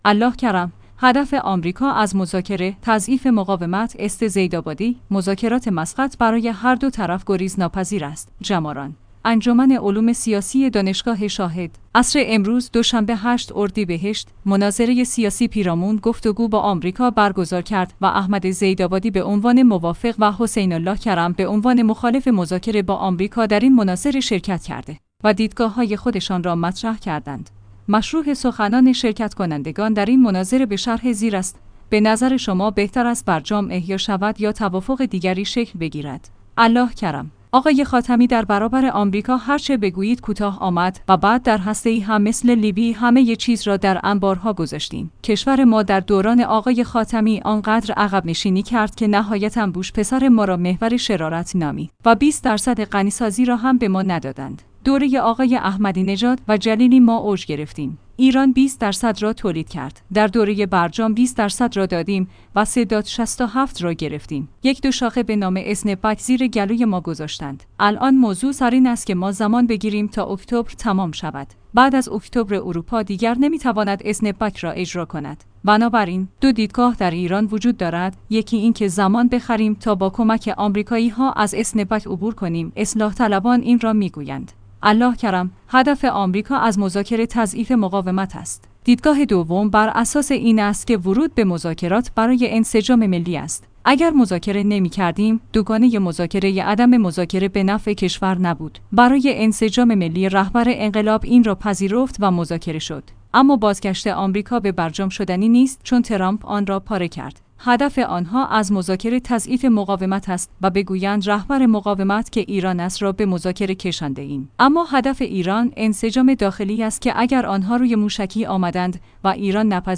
جماران/ انجمن علوم سیاسی دانشگاه شاهد، عصر امروز(دوشنبه 8 اردیبهشت) مناظره سیاسی پیرامون «گفت‌وگو با آمریکا» برگزار کرد